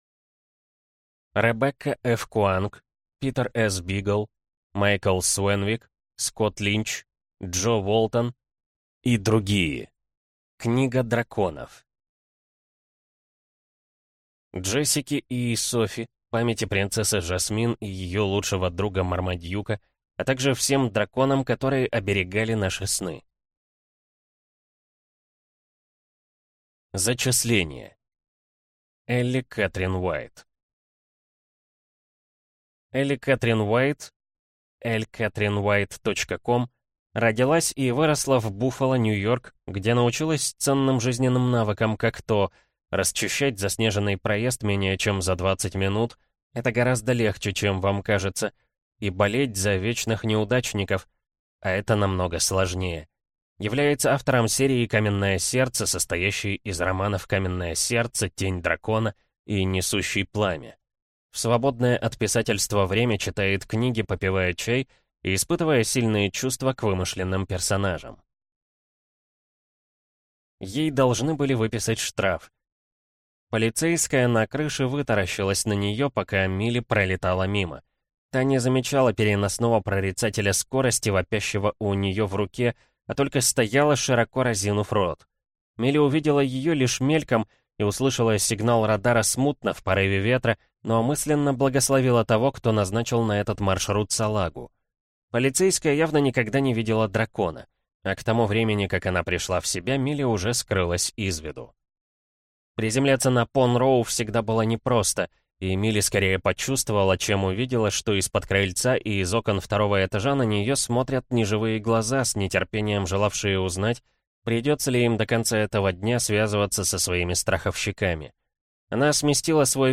Аудиокнига Книга драконов | Библиотека аудиокниг
Прослушать и бесплатно скачать фрагмент аудиокниги